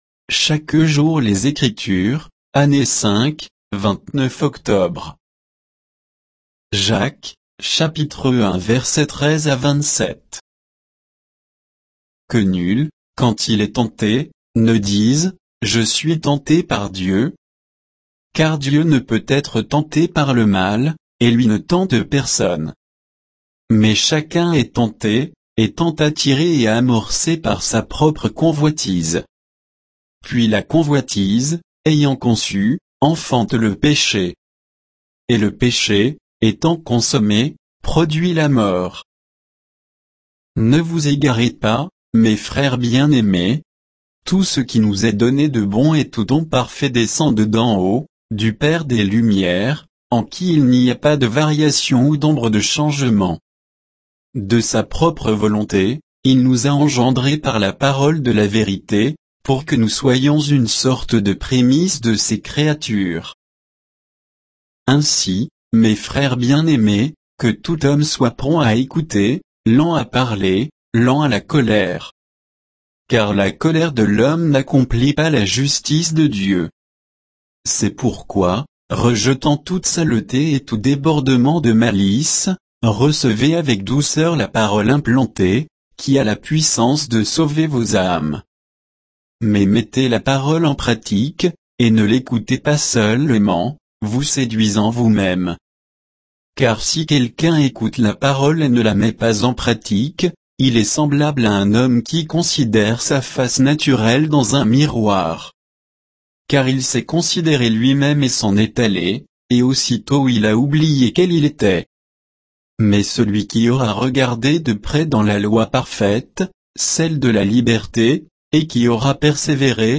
Méditation quoditienne de Chaque jour les Écritures sur Jacques 1